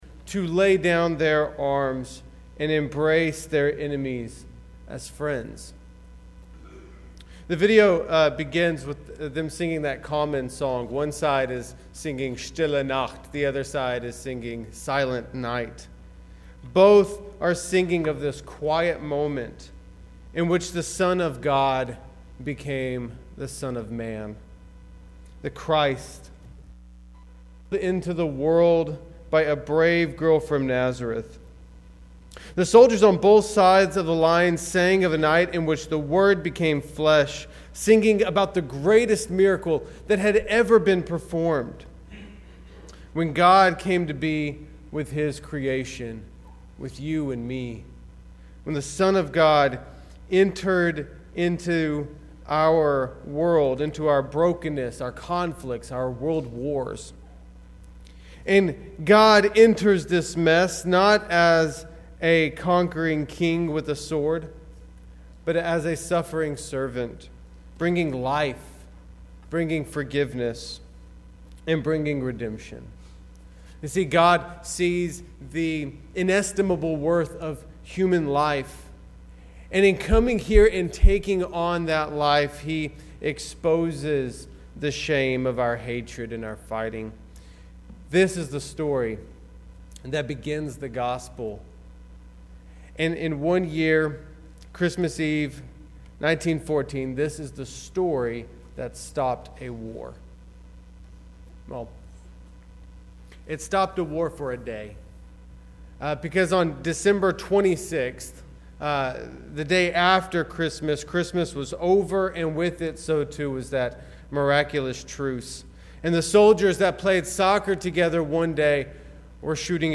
Note: Some of the audio at the beginning of the sermon has been cut off. The sermon began with a video, which you can find here.